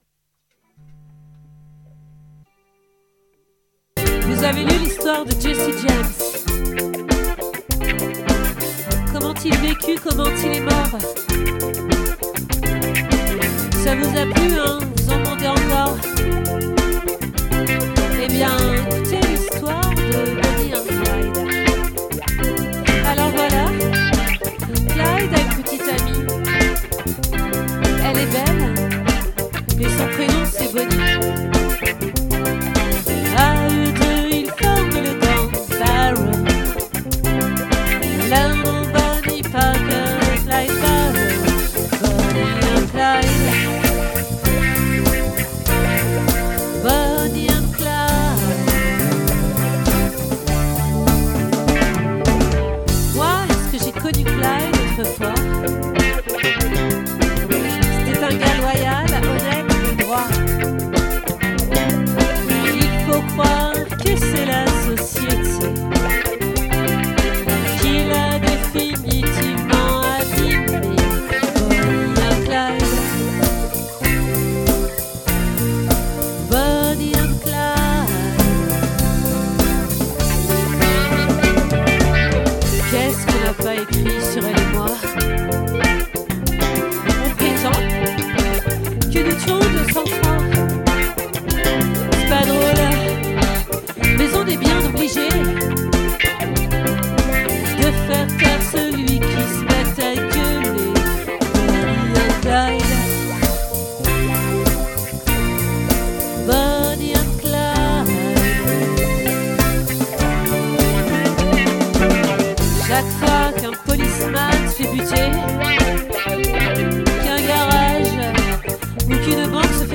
🏠 Accueil Repetitions Records_2024_01_29